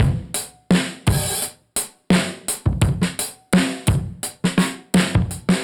Index of /musicradar/dusty-funk-samples/Beats/85bpm/Alt Sound